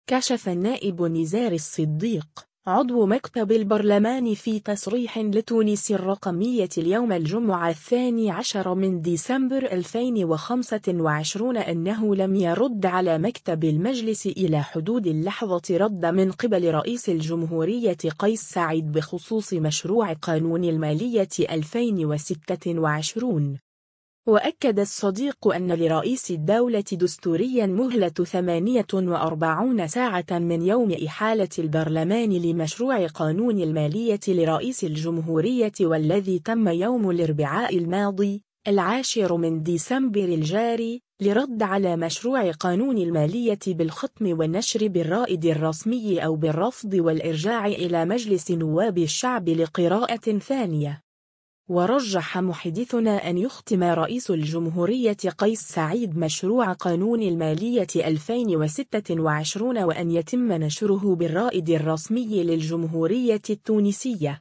كشف النائب نزار الصديق، عضو مكتب البرلمان في تصريح لـ”تونس الرقمية” اليوم الجمعة 12 ديسمبر 2025 أنه لم يرد على مكتب المجلس إلى حدود اللحظة ردّ من قبل رئيس الجمهورية قيس سعيد بخصوص مشروع قانون المالية 2026.